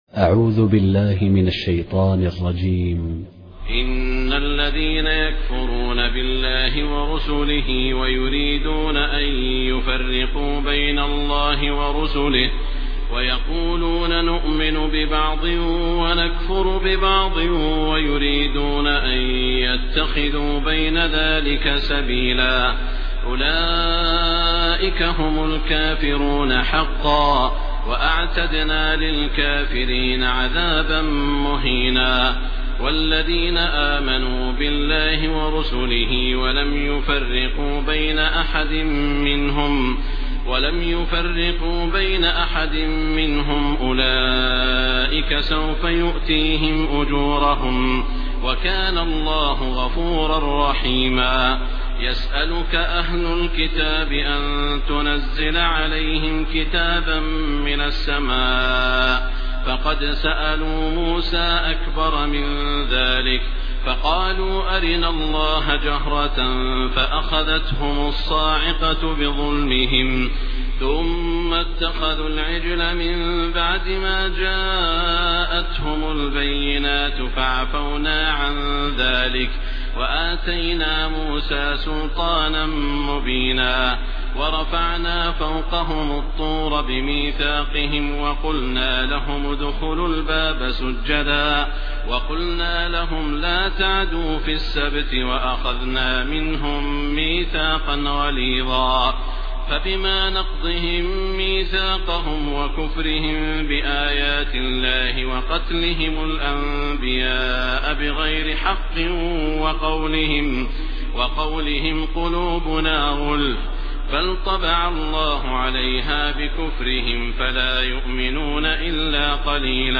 Surah An-Nisa, verses 150-171, reciter Saud Al-Shuraim
Humble, distinctive recitations